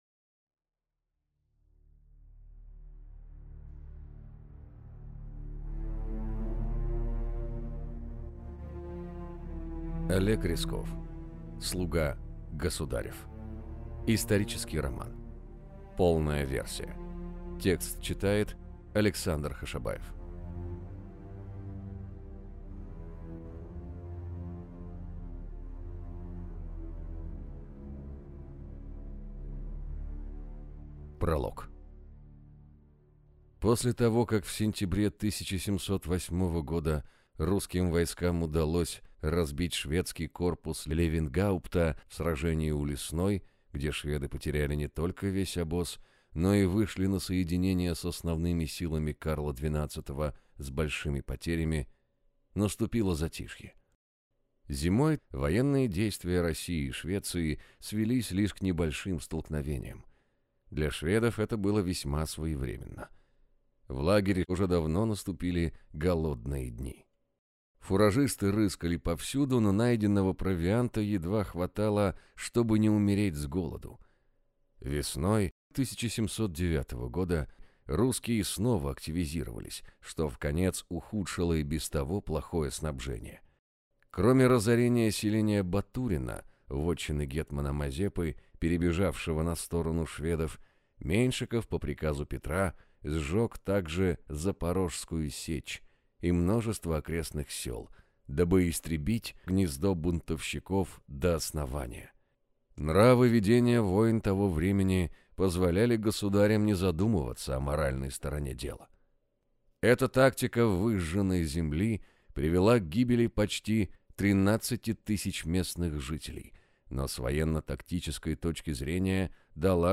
Аудиокнига Слуга государев (полная версия) | Библиотека аудиокниг